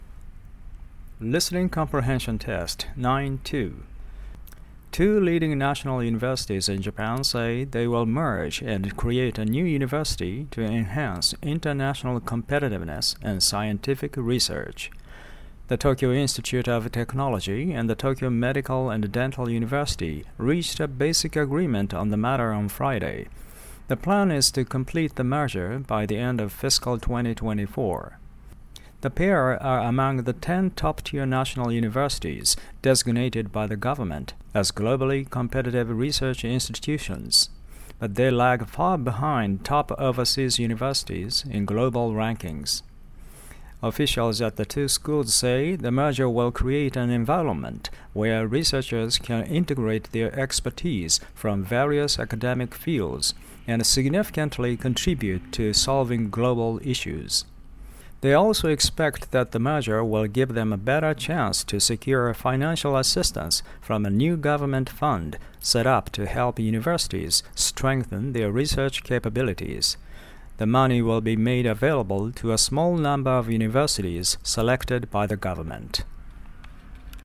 これは著作権の関係で僕が読んでいますが